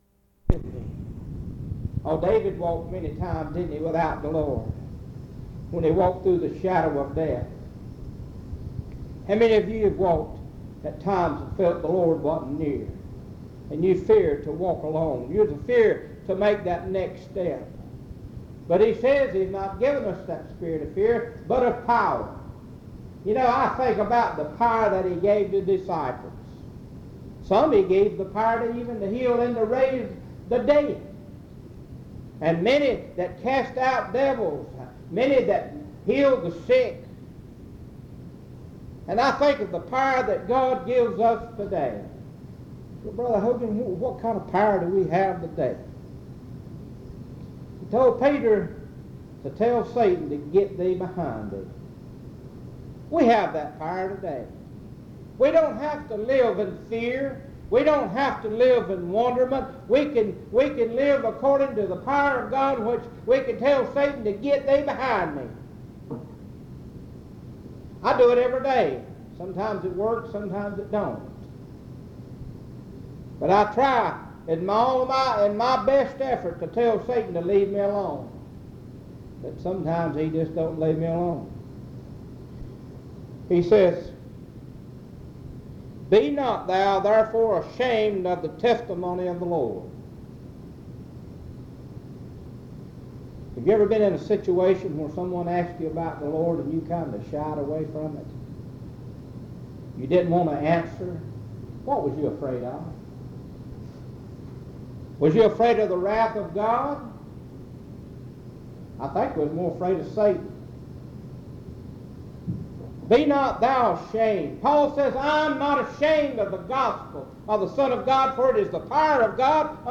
Primitive Baptists
Browns Summit (N.C.) Guilford County (N.C.)